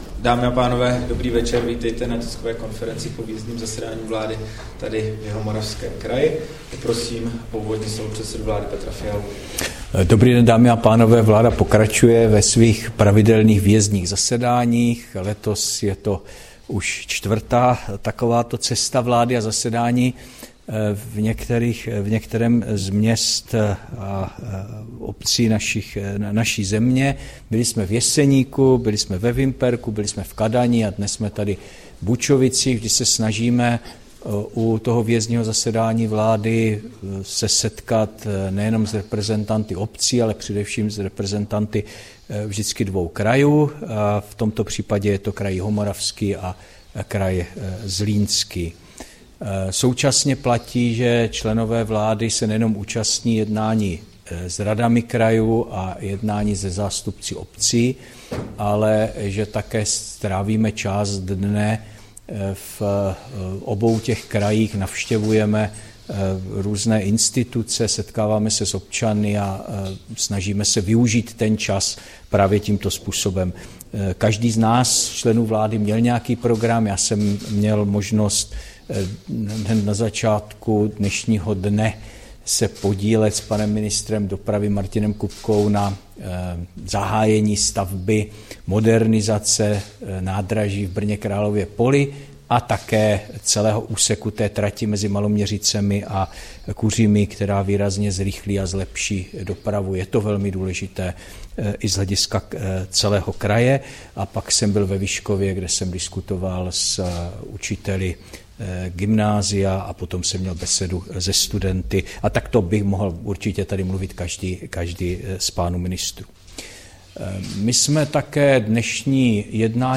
Tisková konference po výjezdním zasedání vlády v Bučovicích, 6. prosince 2023